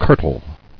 [kir·tle]